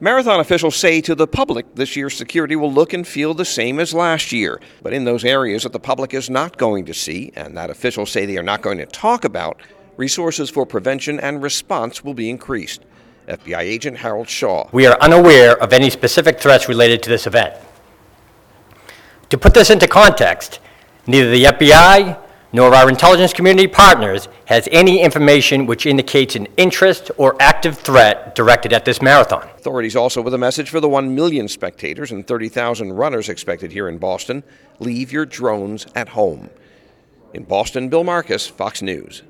Boston Marathon Security Presser
(BOSTON) APRIL 6 – AT A NEWS CONFERENCE IN BOSTON WEDNESDAY OFFICIALS SAY THE PAST YEAR’S TERRORIST ATTACKS IN SAN BERNADINO, PARIS, AND BELGIUM WILL INFLUENCE SECURITY ARRANGEMENTS FOR THE APRIL 18TH BOSTON MARATHON.